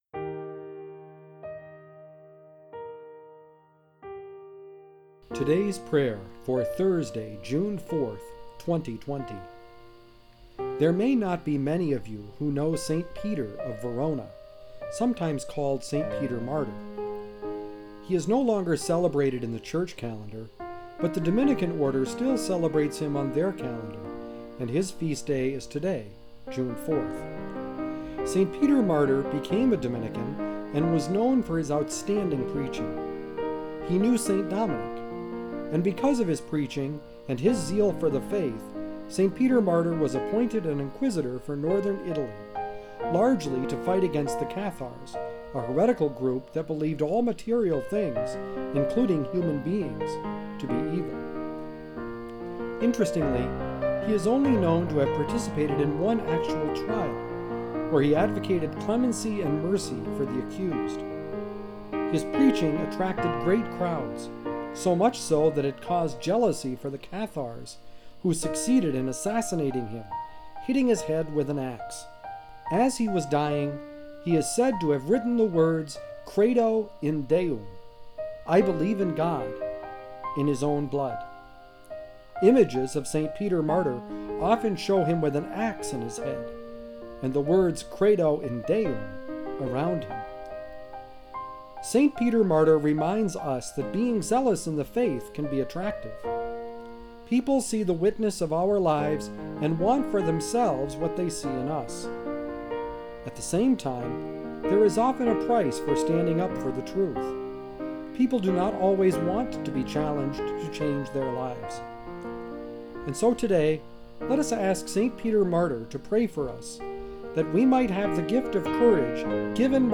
Music: Cheezy Piano Medley by Alexander Nakarada